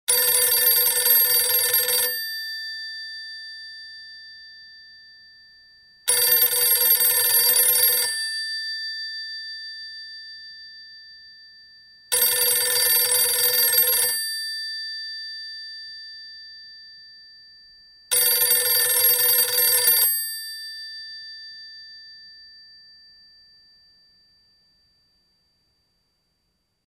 Звуки звонков
Звук звонка старинного дискового телефона